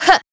Ripine-Vox_Attack1.wav